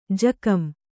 ♪ jakam